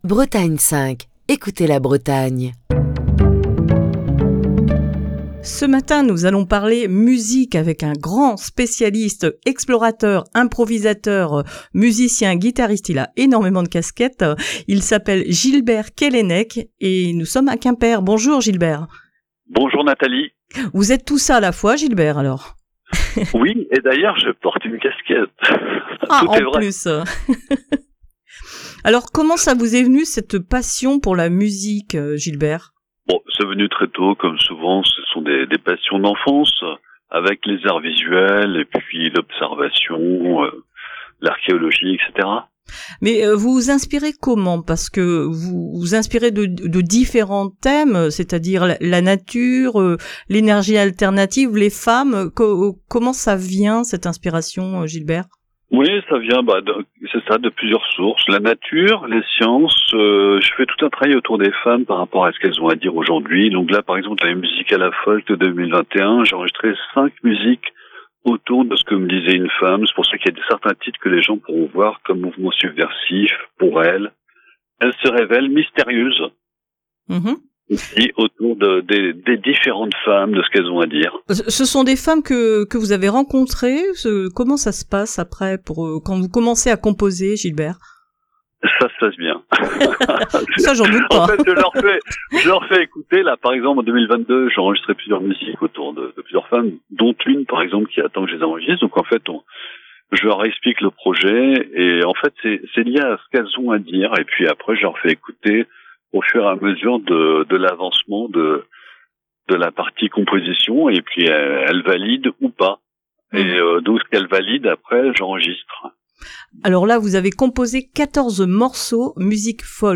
Émission du 12 septembre 2022.